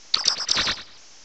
cries
milcery.aif